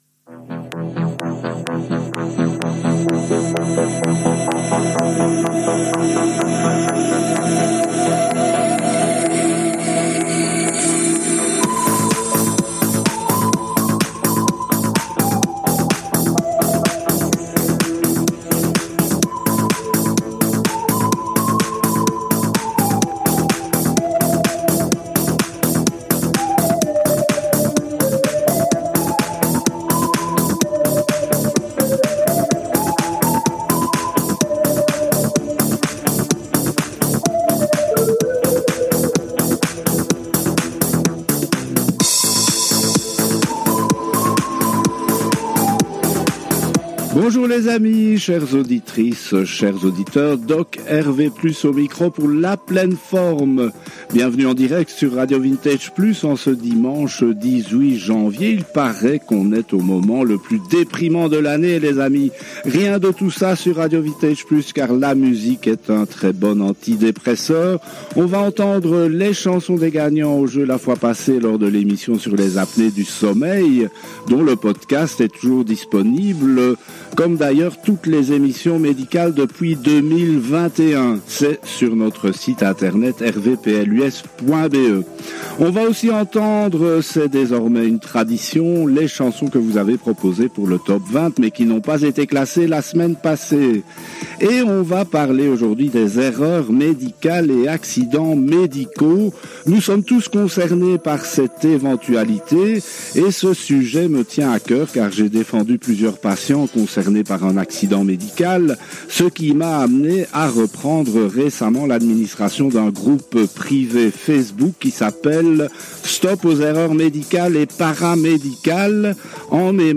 Par ailleurs au niveau musical, les auditeurs testent leur mémoire musicale car les noms des artistes et les titres des chansons ne sont révélés qu’après la diffusion des chansons.